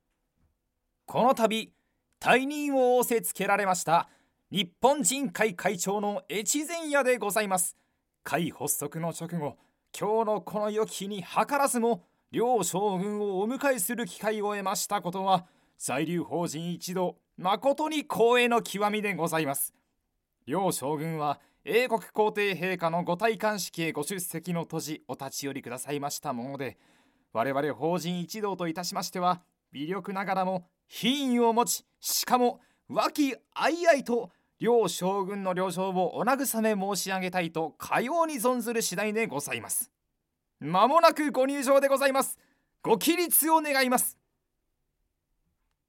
セリフB